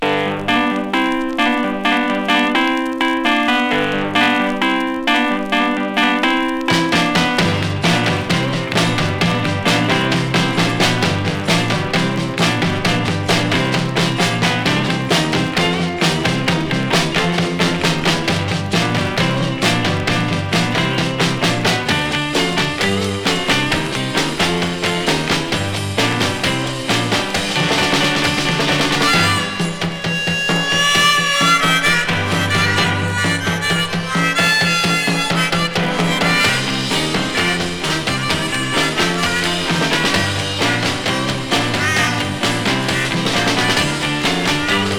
※キズ多ですが、感じさせない迫力ある音のMono盤です。
Rock, Surf, Garage, Lounge　USA　12inchレコード　33rpm　Mono